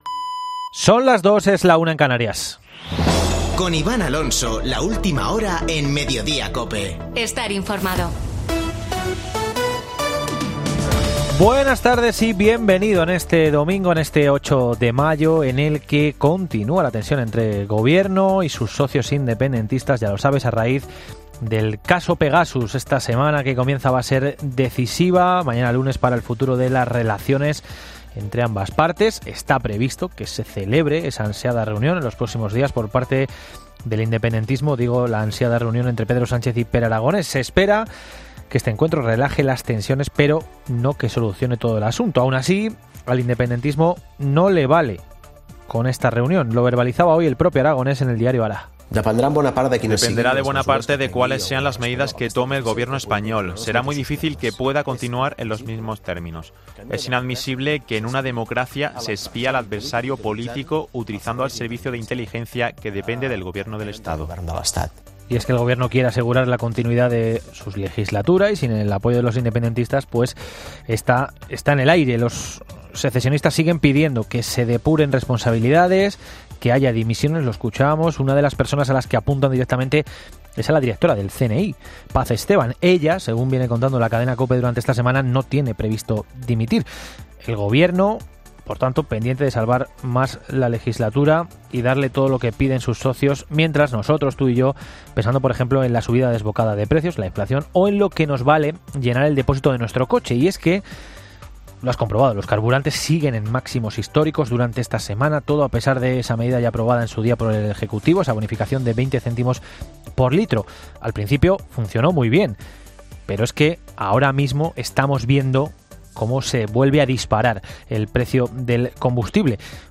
Boletín de noticias de COPE del 8 de mayo de 2022 a las 14.00 horas